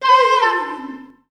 Index of /90_sSampleCDs/Voices_Of_Africa/VariousPhrases&Chants
21_VocalPhrase.WAV